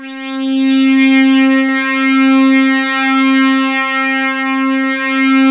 SWEEP 2.wav